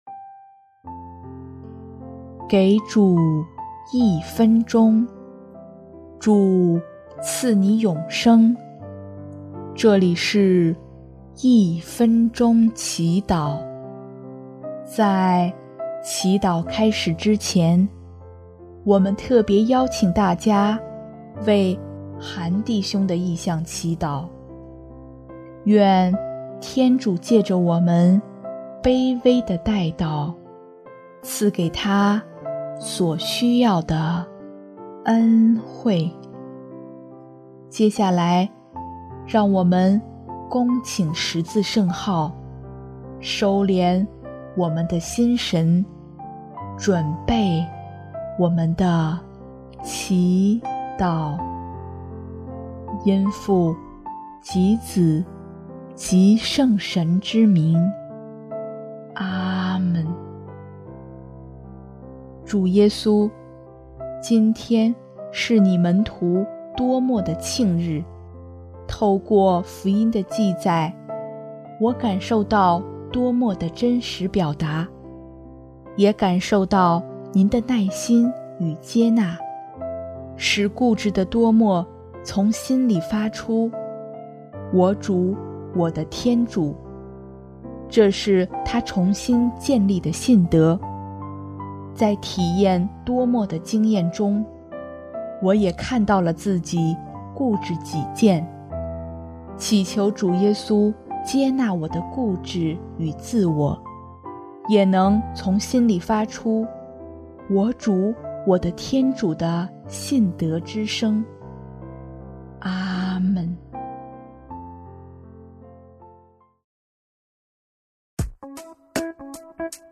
音乐： 主日赞歌